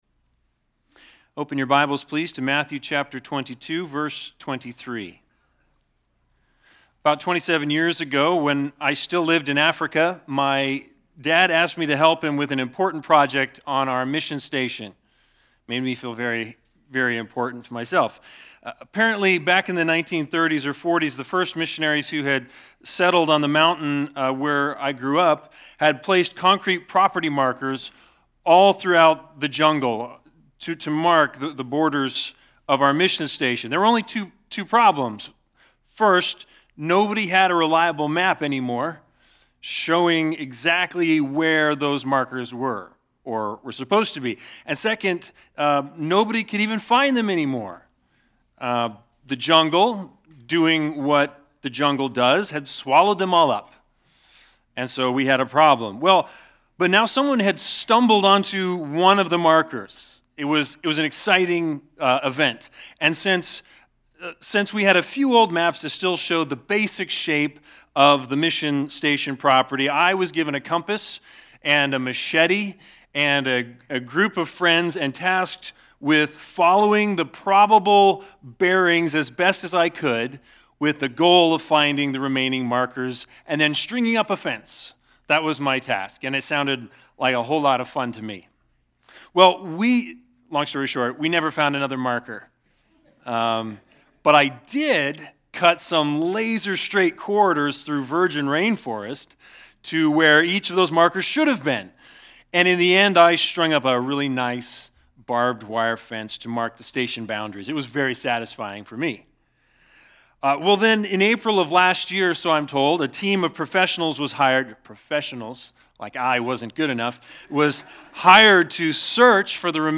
Sunday Sermons Big Idea